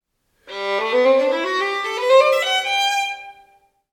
Mit den sogenannten Durtonleitern assoziieren wir eine fröhliche, mit Molltonleitern eine traurige Stimmung:
Durtonleiter